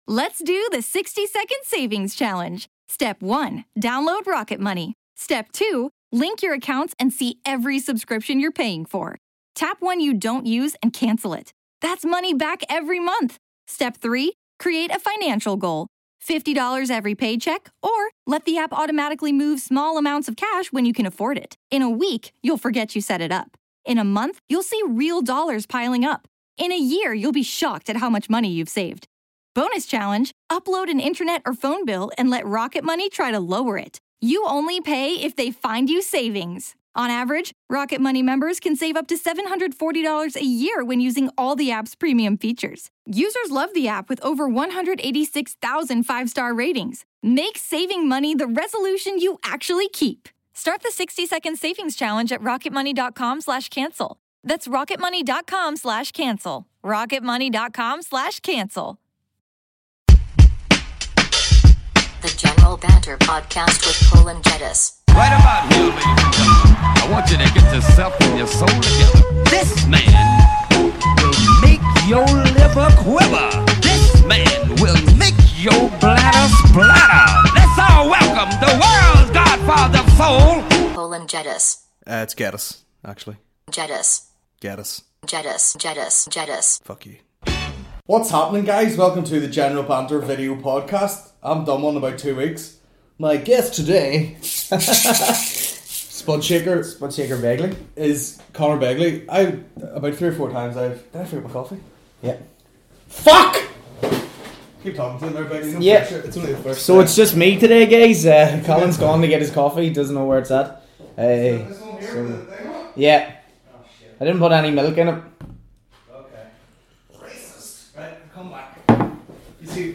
a Comedy podcast